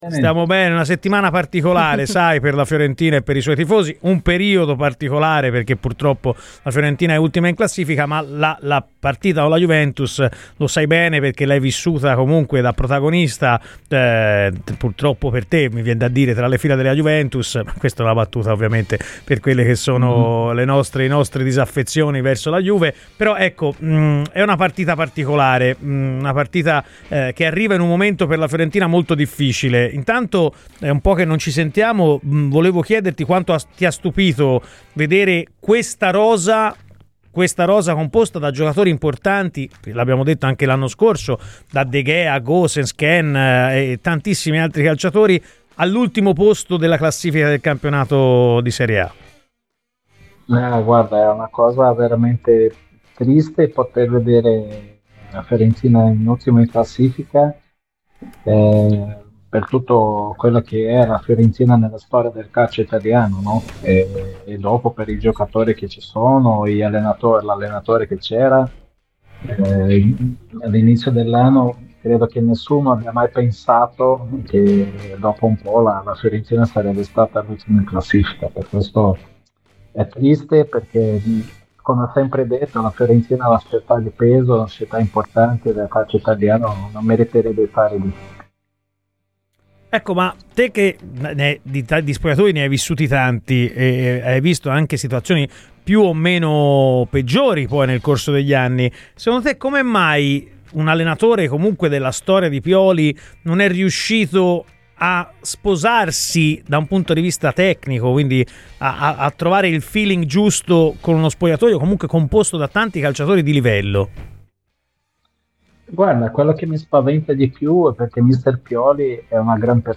L'ex portiere Rubinho , è intervenuto a Radio FirenzeViola durante"Palla al Centro" per parlare della situazione della Fiorentina.